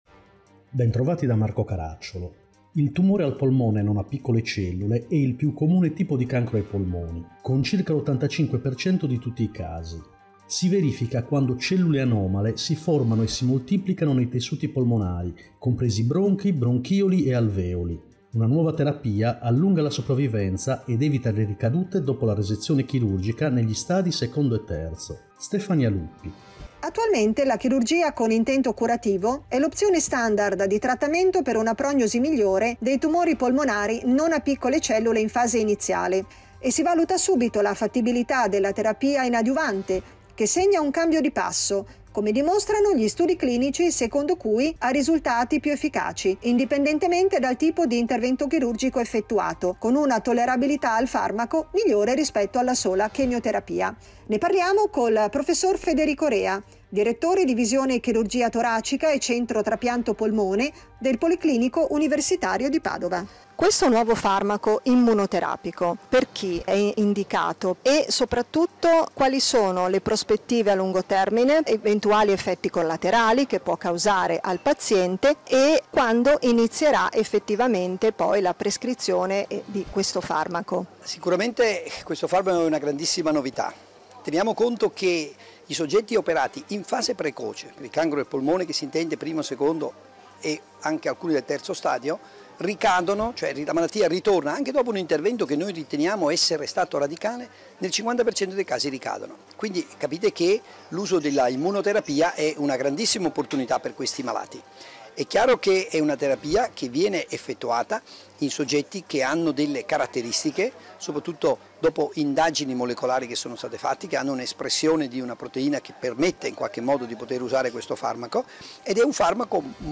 Puntata con sigla